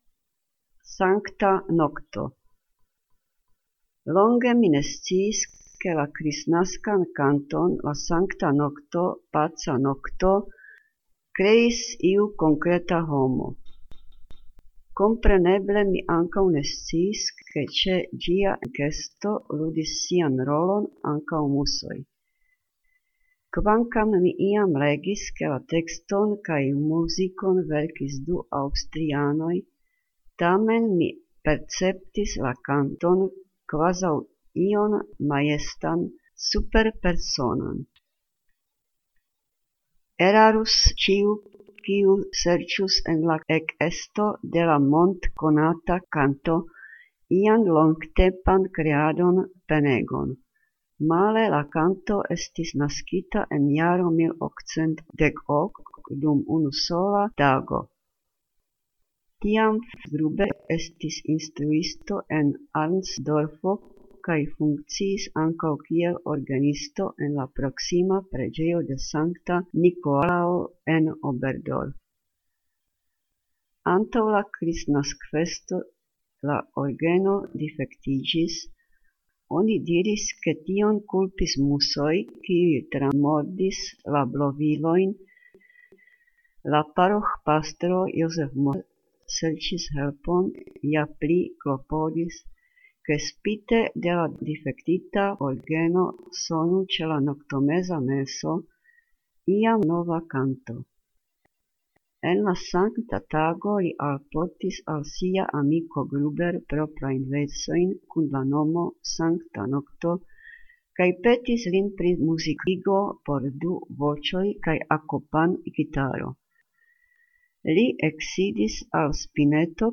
Audionahrávka básně Prosinec od Fráni Šrámka (paralelně v češtině a esperantu)